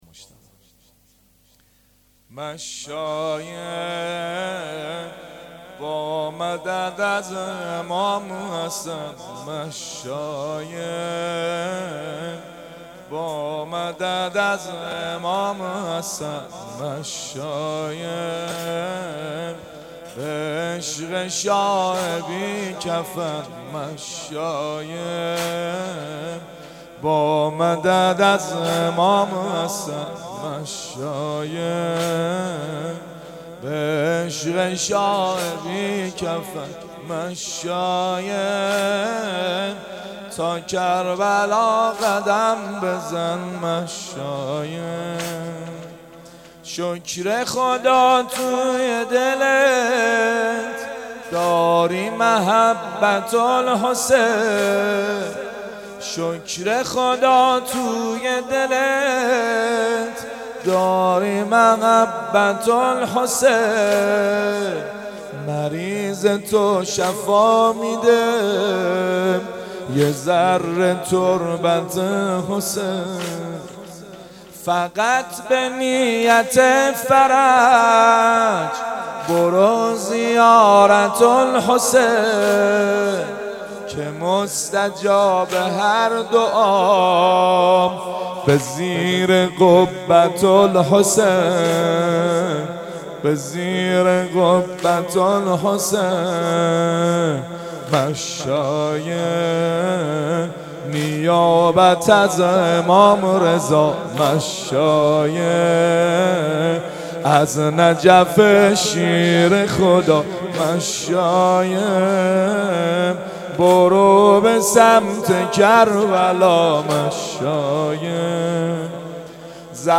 اقامه عزای دهه اول صفر _ شب سوم